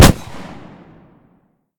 pistol-shot-07.ogg